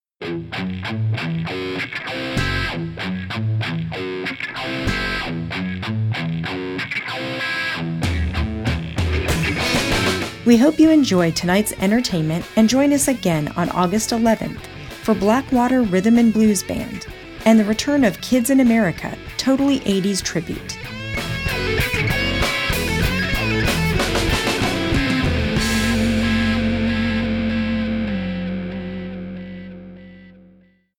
Next Month Message:
Also note that these are produced samples with background music added which is an add on service we provide.